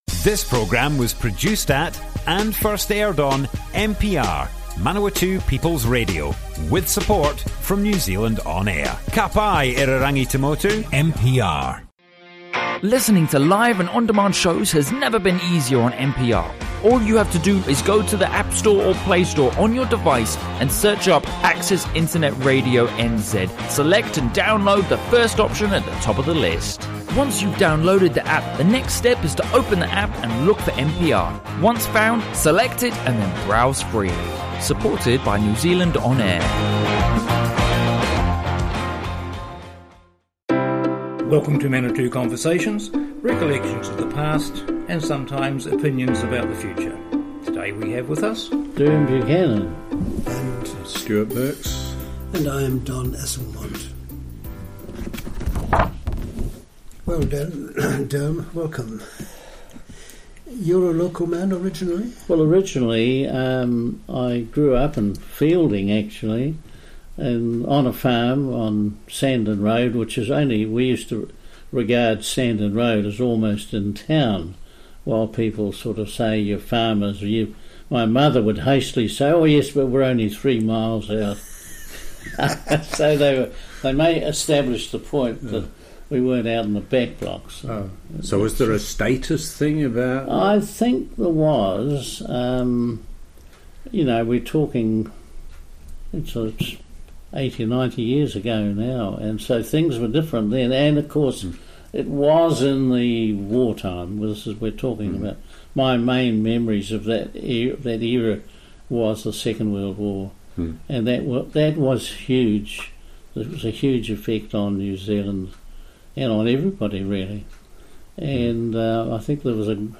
Manawatū Conversations More Info → Description Broadcast on Manawatu People's Radio, 13th August 2019.
oral history